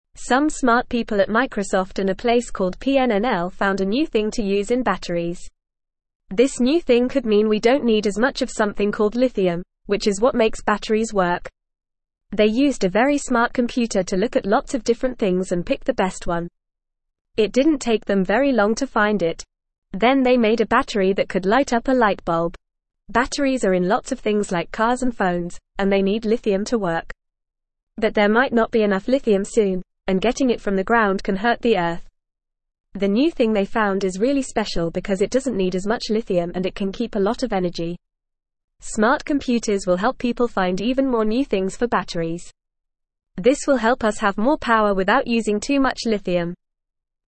Fast
English-Newsroom-Beginner-FAST-Reading-New-Battery-Discovery-Less-Lithium-More-Power.mp3